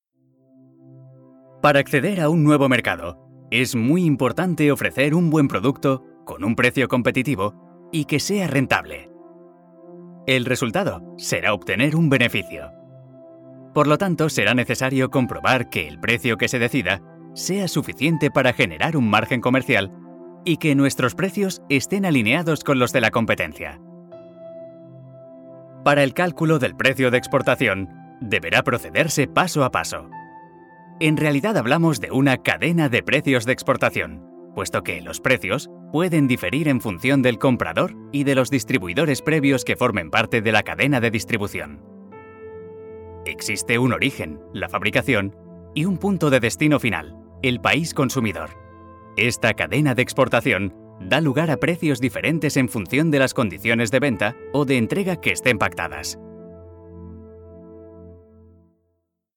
Mi voz es amable. Tengo un amplio abanico de registros: serio, gracioso, institucional...
kastilisch
Sprechprobe: eLearning (Muttersprache):